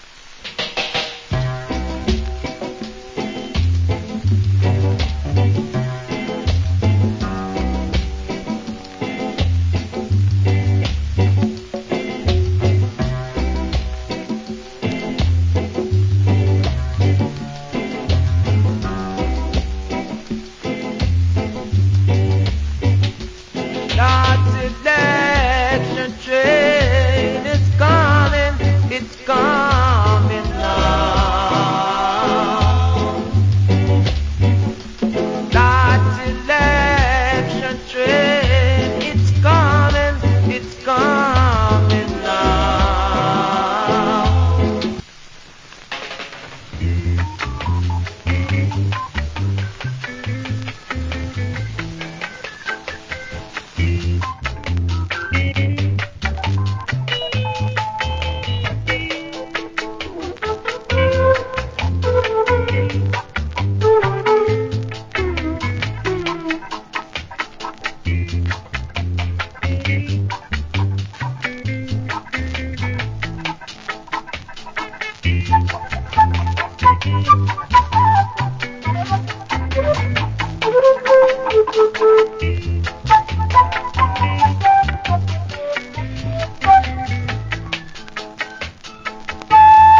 Great Reggae Vocal.